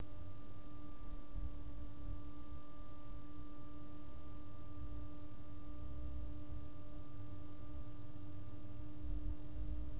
Maximale Umdrehung bei 2400 U/min
enermax_warp_80_max.wav